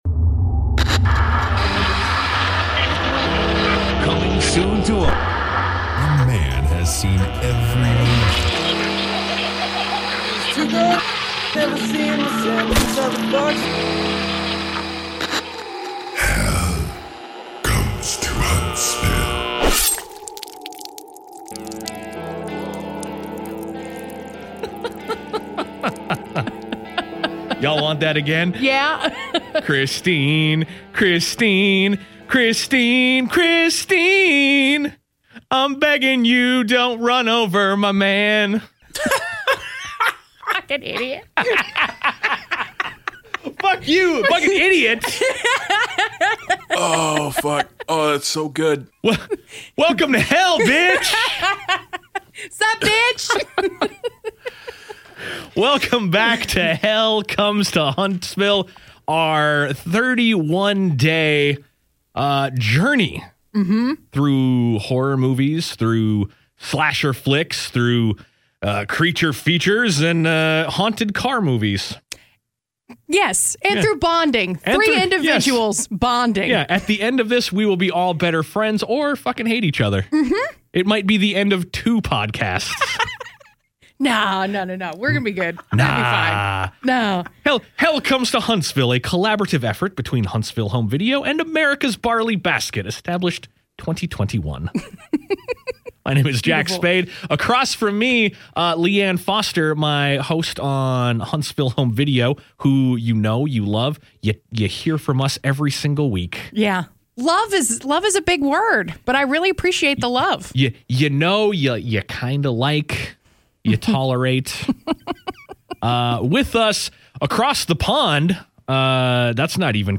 Your new favorite trio is chattin' up Christine! See what they think and tune in for 31 movies in 31 days for October!